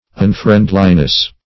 [1913 Webster] -- Un*friend"li*ness, n.